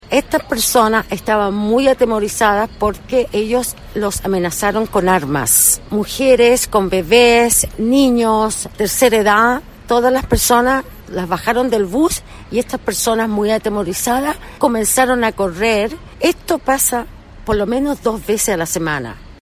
Una vecina del sector entregó mayores antecedentes de la situación que, a su juicio, es reiterada.